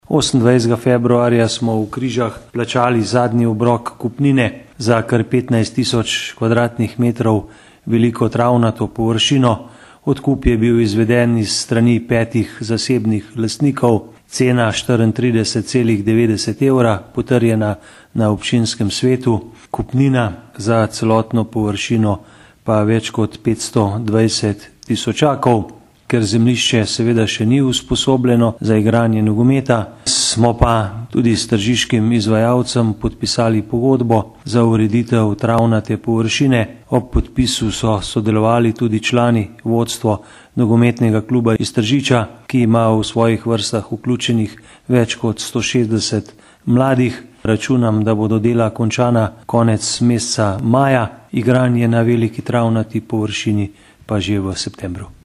izjava_zupanobcinetrzicmag.borutsajoviconogometnemigriscuvkrizah.mp3 (1,3MB)